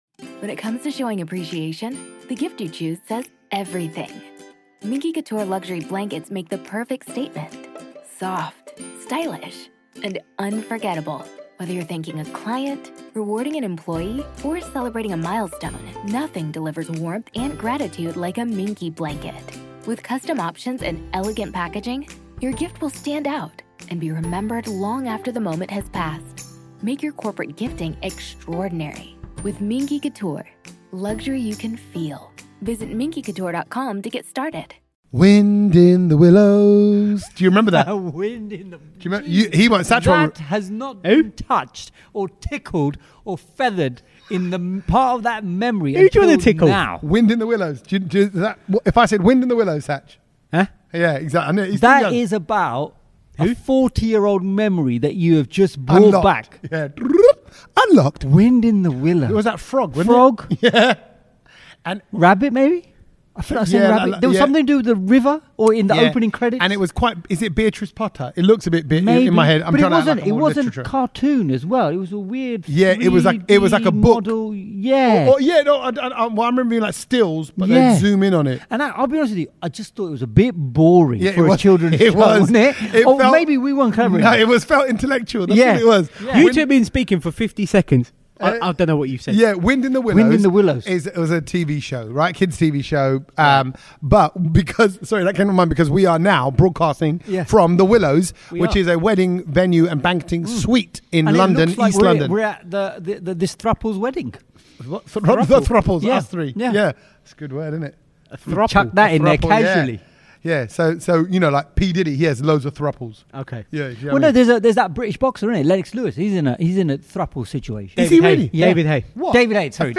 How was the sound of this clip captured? Headliner Embed Embed code See more options Share Facebook X Subscribe Recorded at The Willows Banqueting suite in East London, we are full on enjoying the heatwave!